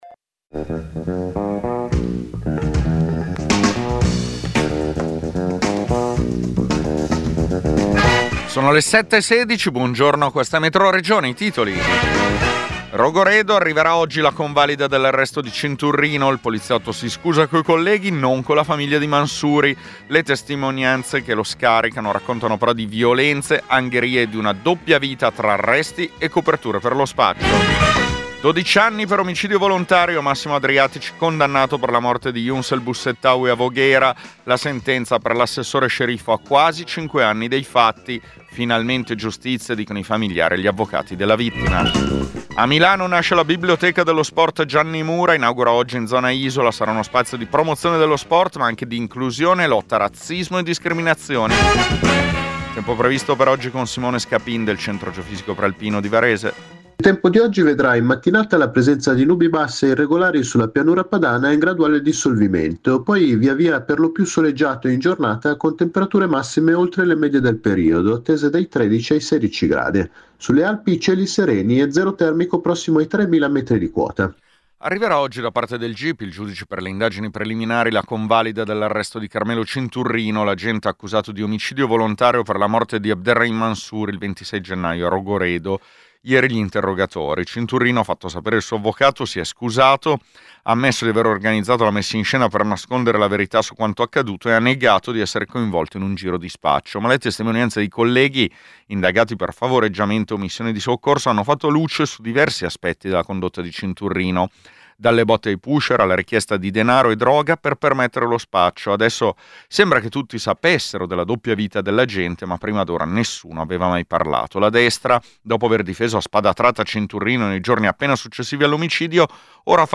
Metroregione è il notiziario regionale di Radio Popolare.